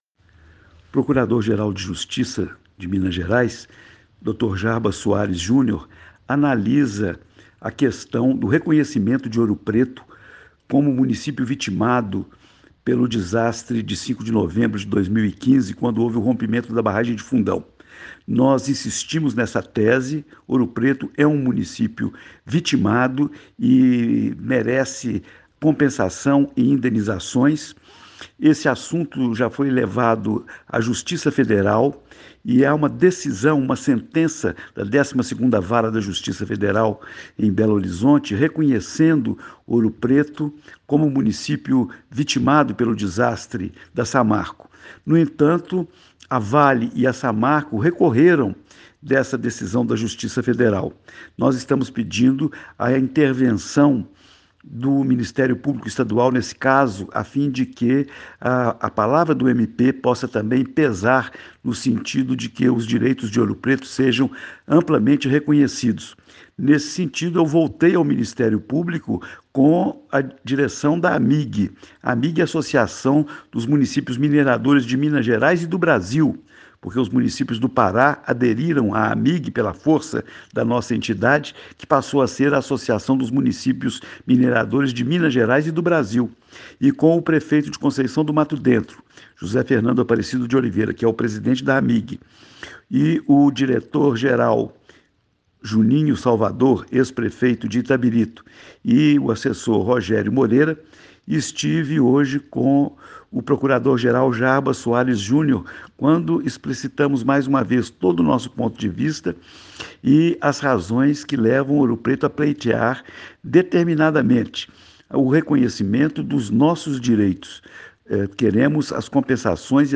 Prefeito Angelo Oswaldo fala sobre a reunião de hoje com o procurador Geral de Justiça de Minas